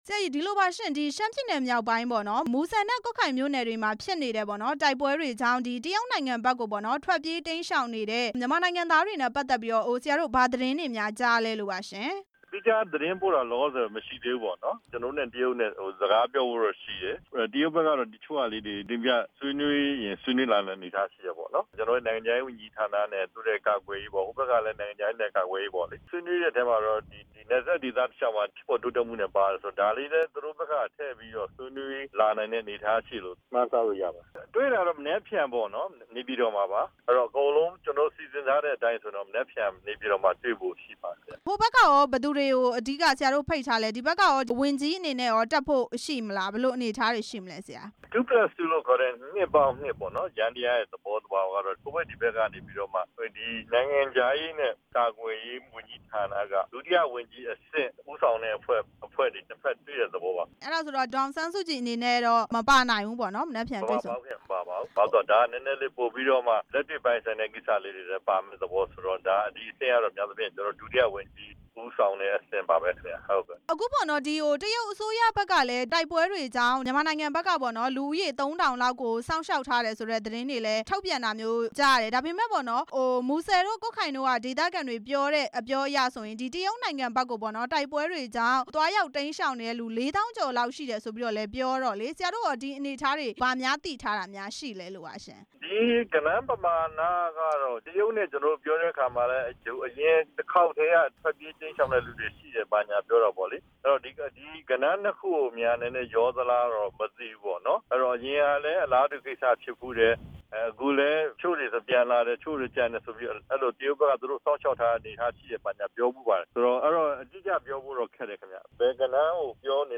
နိုင်ငံခြားရေးဝန်ကြီးဌာန ညွန်ကြားရေးမှူးချုပ် ဦးကျော်ဇေယျနဲ့ မေးမြန်းချက်